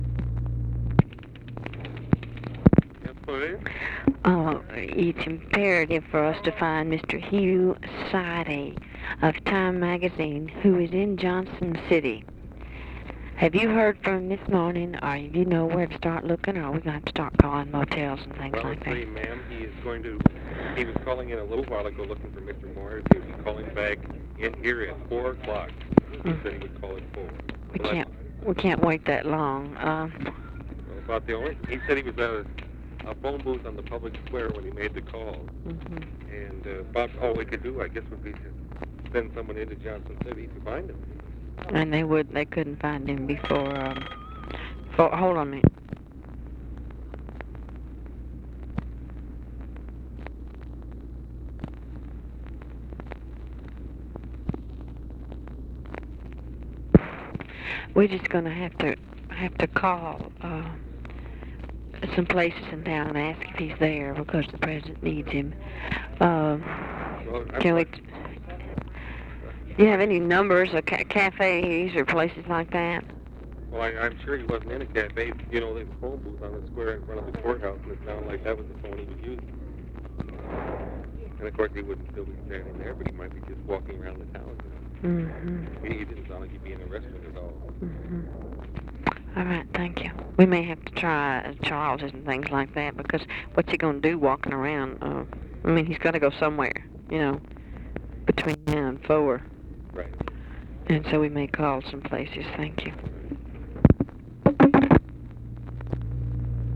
Conversation with OFFICE SECRETARY and (possibly) SIGNAL CORPS OPERATOR, January 2, 1964
Secret White House Tapes